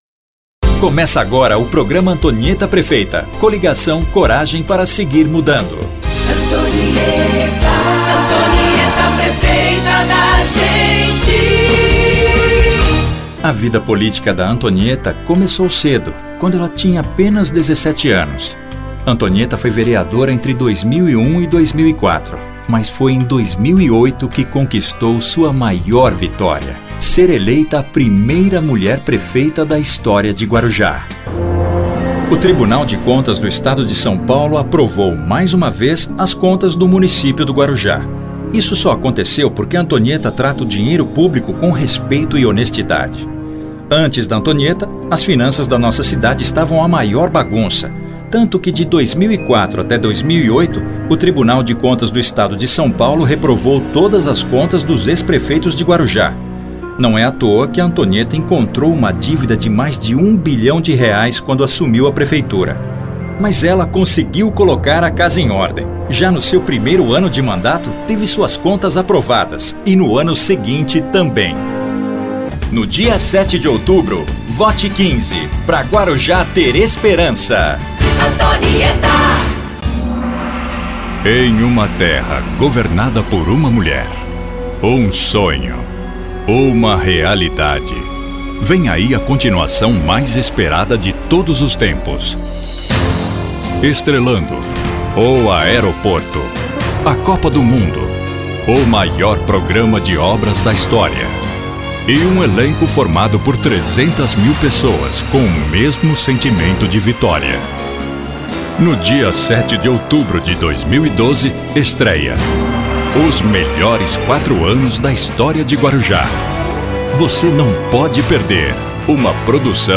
Locução para Campanha Política da Prefeitura do Guarujá 2012